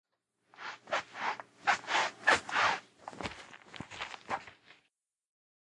地毯上的笔
描述：这是笔被拖过地毯的表面，也可能听起来像砾石上的刺耳的脚步声
标签： 地毯 粗糙 中风 苛刻
声道立体声